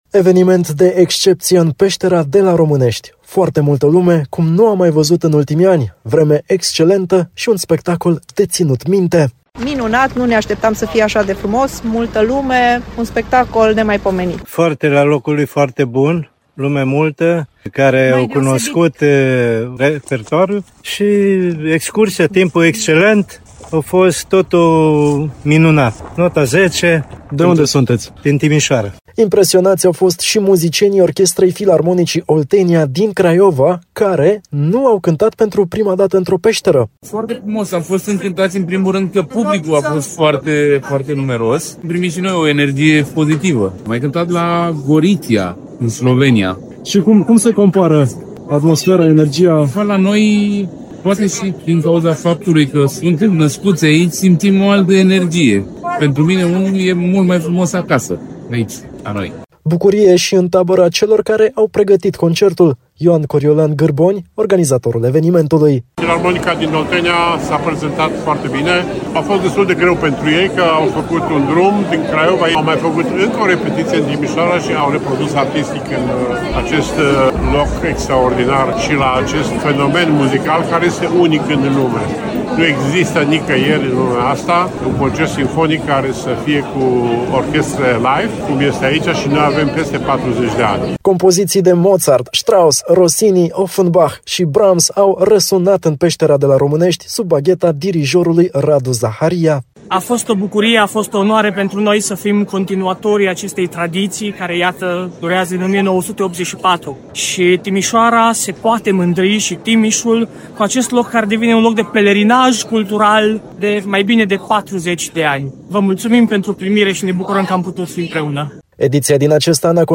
La concertul de la Românești a asistat și prefectul județului Timiș, Cornelia Micicoi.